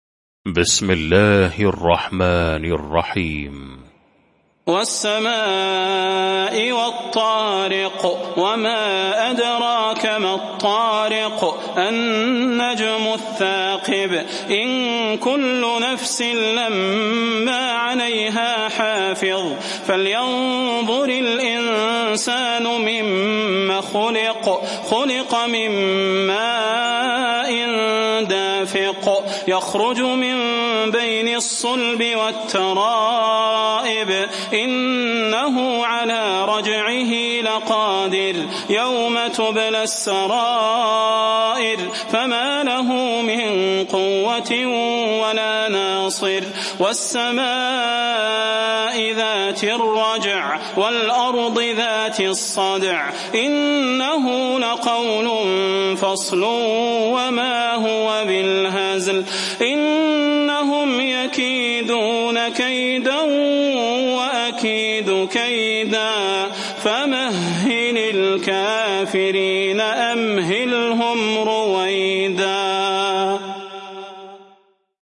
المكان: المسجد النبوي الشيخ: فضيلة الشيخ د. صلاح بن محمد البدير فضيلة الشيخ د. صلاح بن محمد البدير الطارق